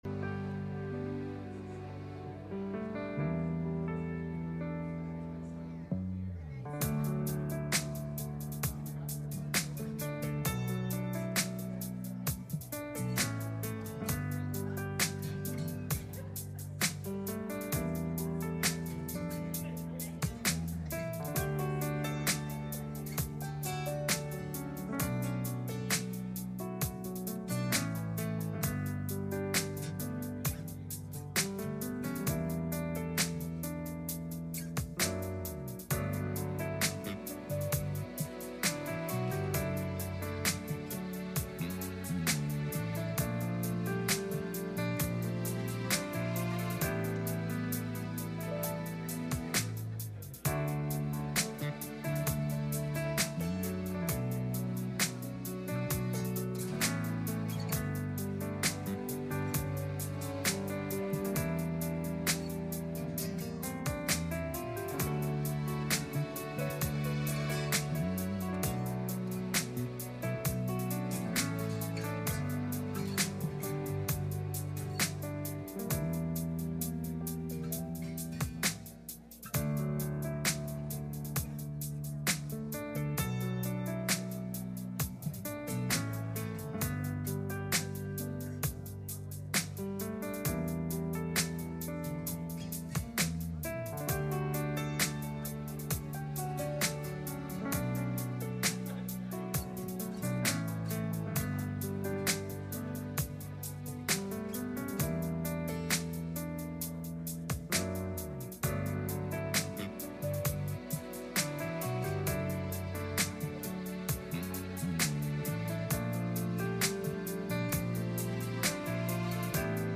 Passage: John 20:19 Service Type: Sunday Morning